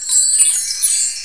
ZVONEK.mp3